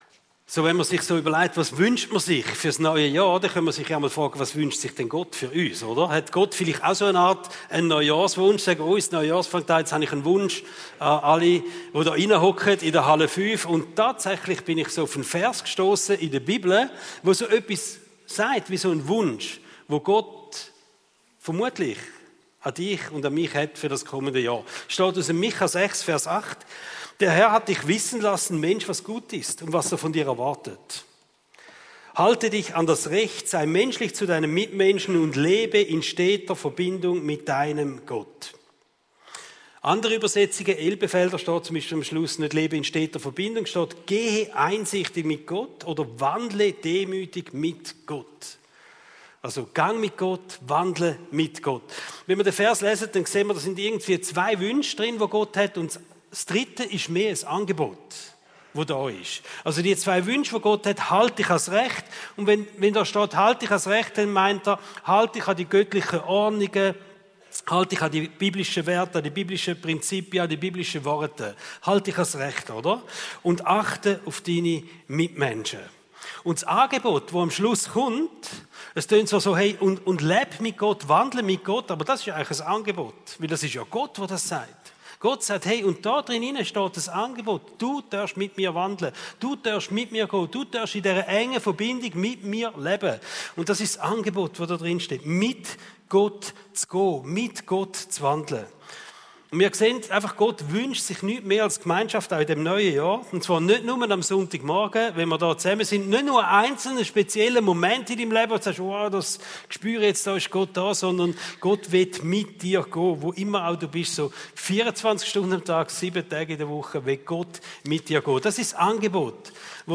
Regelmässig die neusten Predigten der GvC Frauenfeld